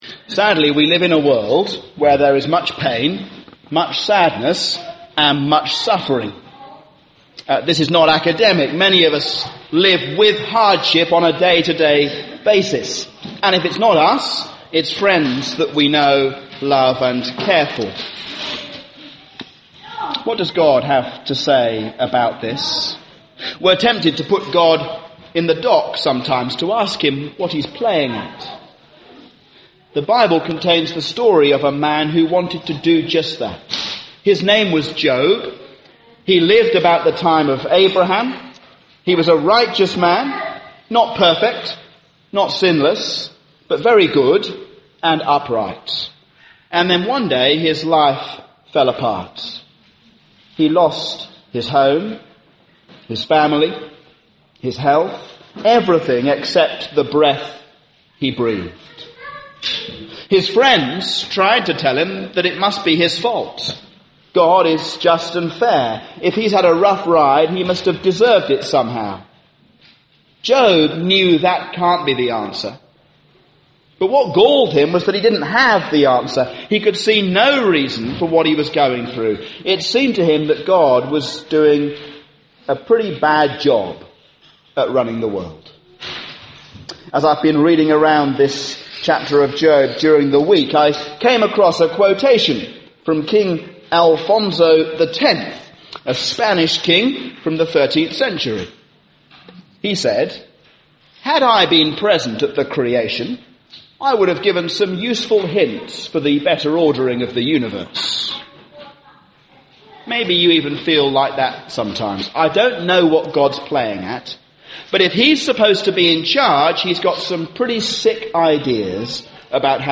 A sermon on Job 38